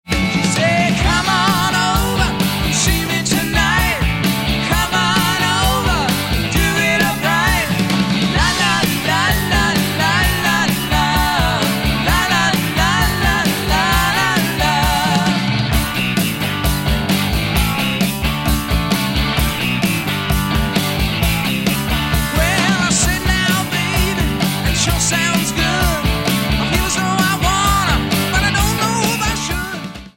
guitar, synthesizer, vocals
drums, percussion
guitar, keyboards, vocals
Album Notes: Recorded at Can-Base Studios, Vancouver, Canada